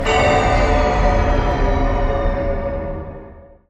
без слов
страшные
жуткие
часы
Зловещий звук часов